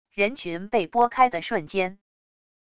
人群被拨开的瞬间wav音频